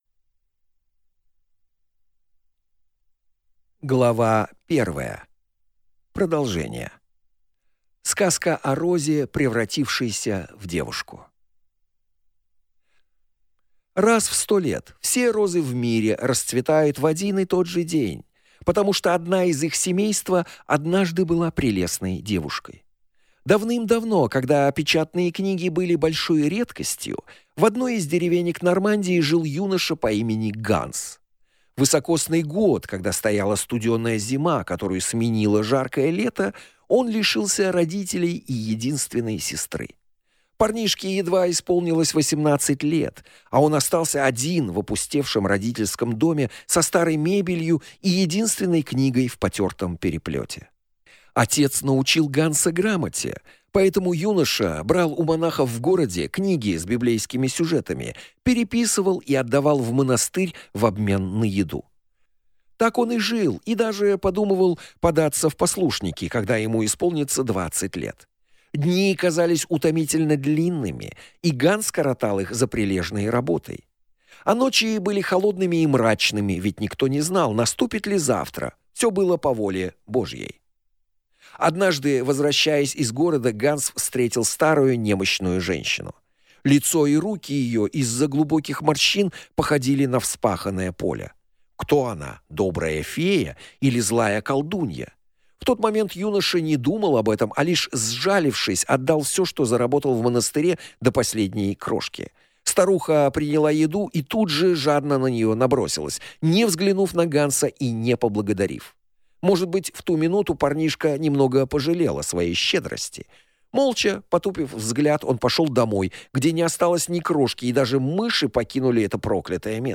Aудиокнига Дом на колесах